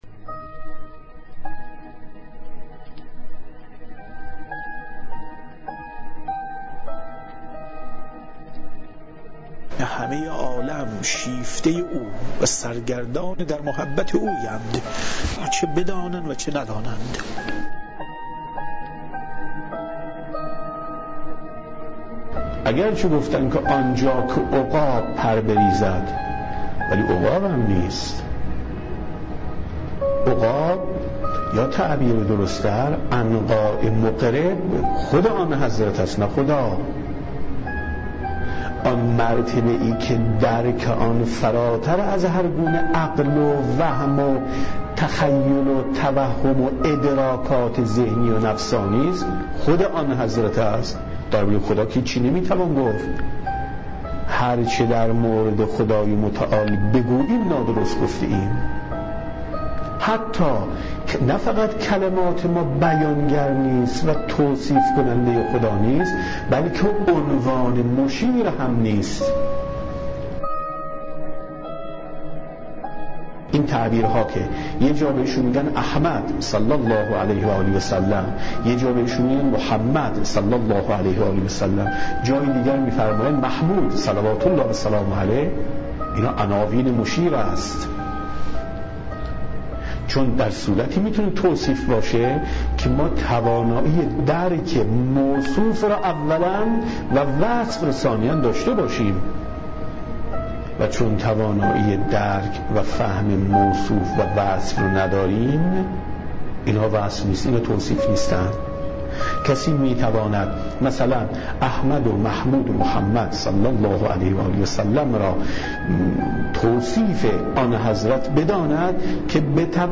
آواهنگ، پادكست، عيد مبعث، پيامبر اعظم